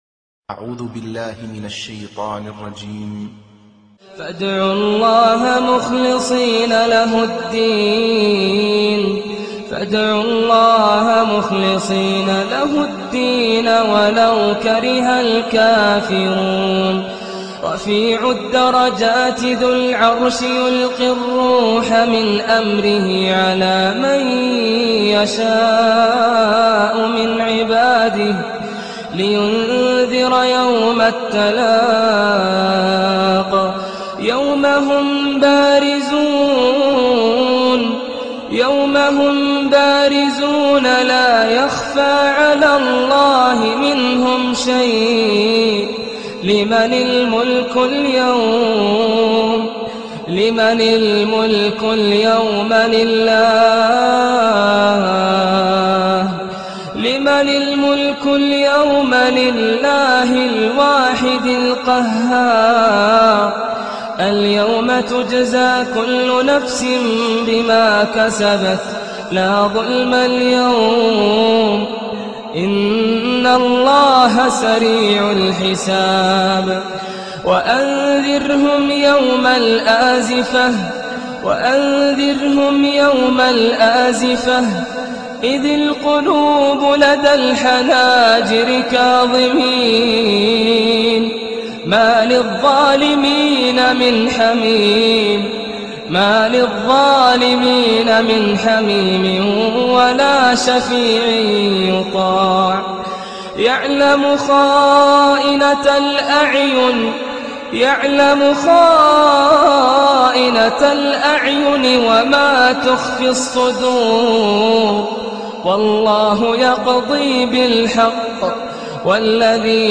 تلاوة خاشعة ومؤثرة